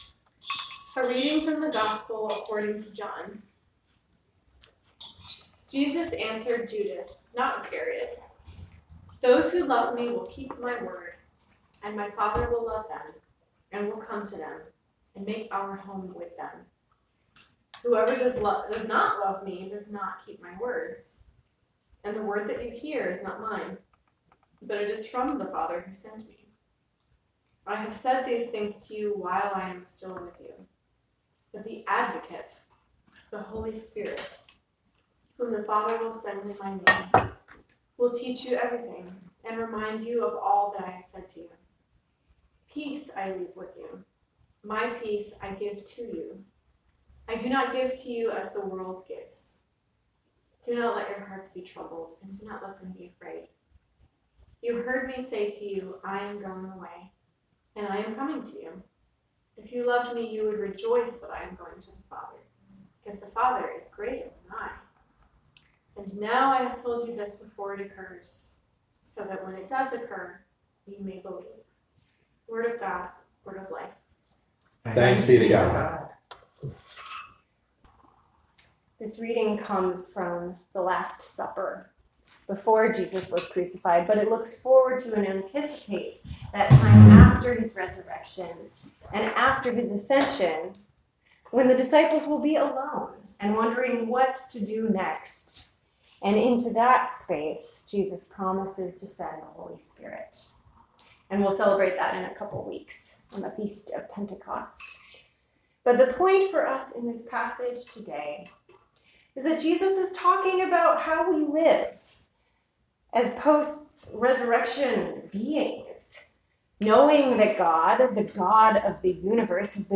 May 27, 2019 Sermon
Posted in: Sermons